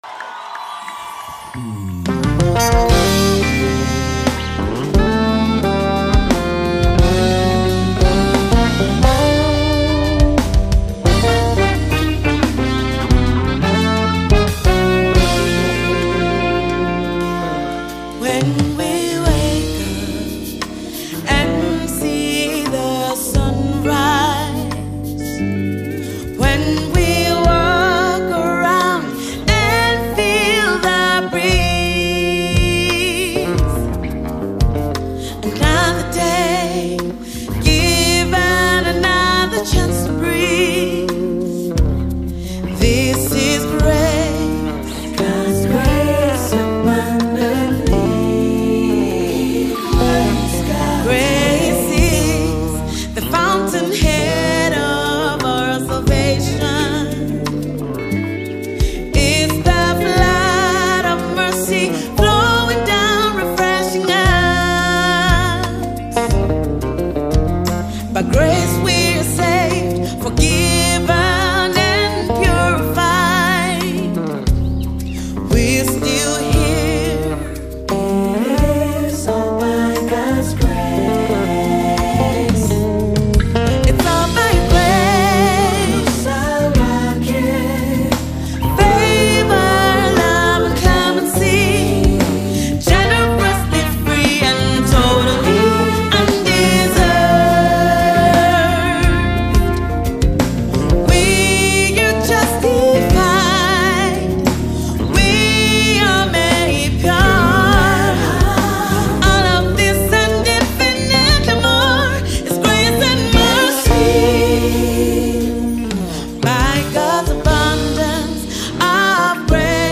Gospel-Jazz